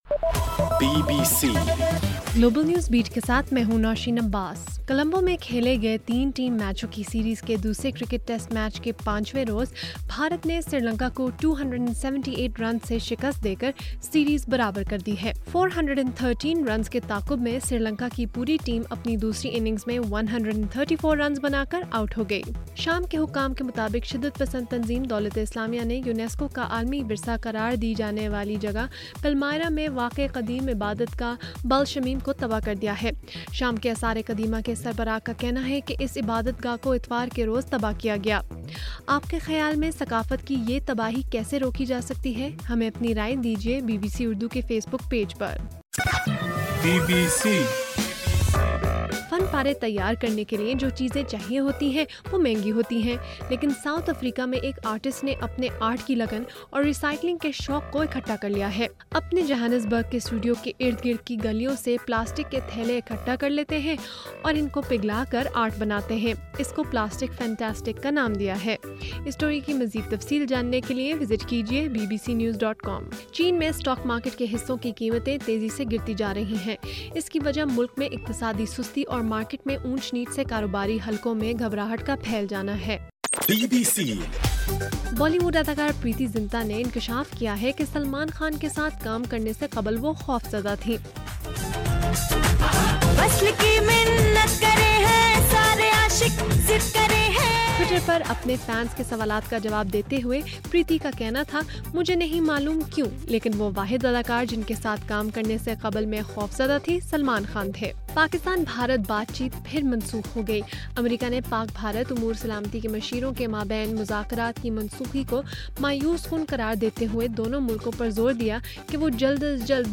اگست 24: رات 10 بجے کا گلوبل نیوز بیٹ بُلیٹن